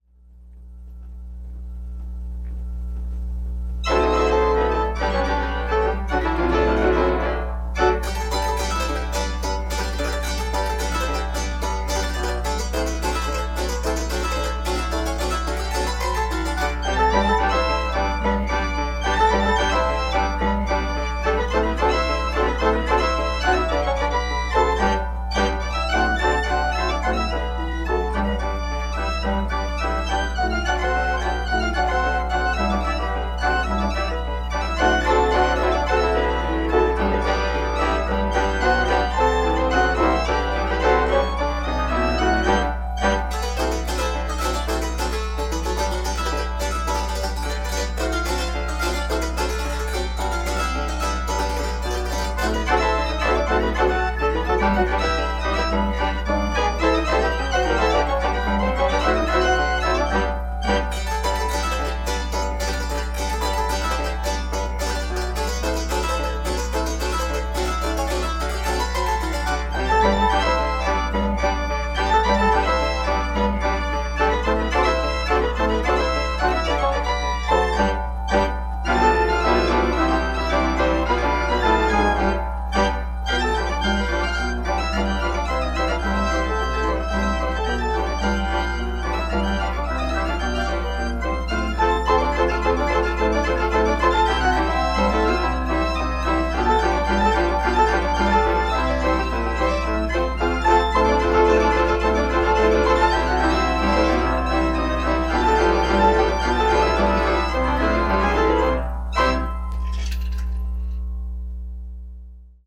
Unknown Ragtime Tune on North Tonawanda Pianolin
We're nearing completion of repairs to a North Tonawanda Pianolin
coin-piano (ca. 1906-15) here in the Guinness collection and I am